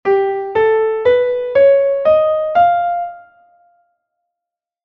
escala2.mp3